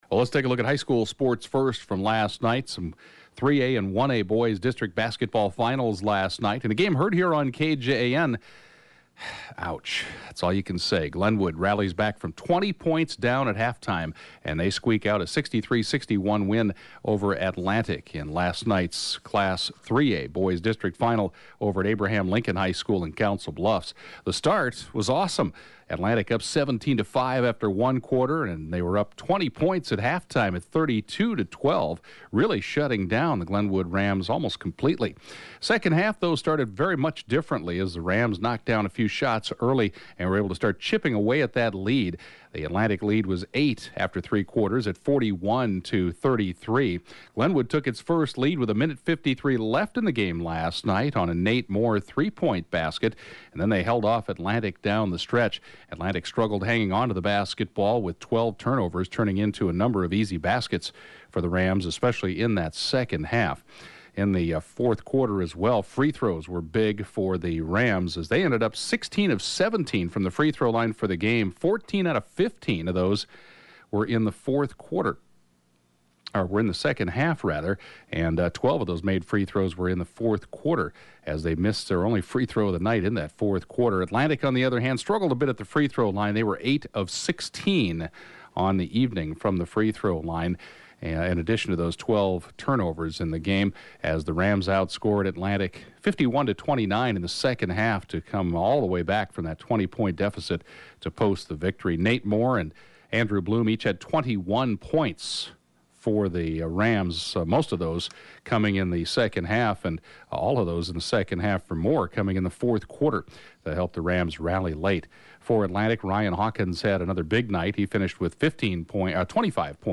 (Podcast) KJAN Morning Sports report, 5/18/2016
The 7:20-a.m. Sportscast